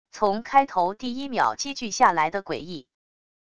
从开头第一秒积聚下来的诡异wav音频